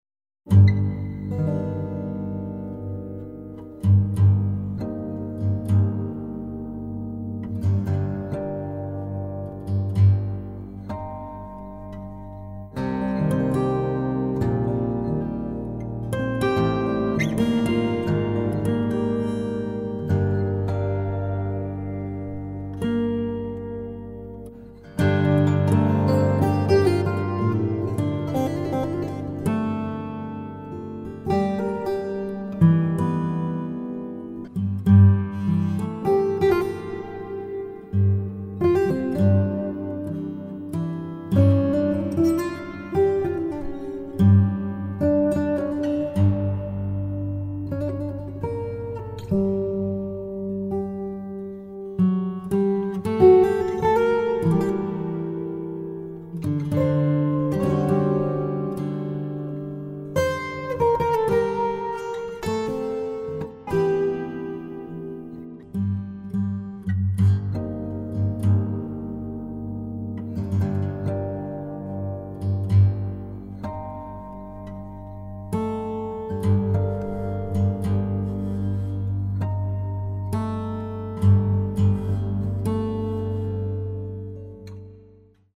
baritone guitar duets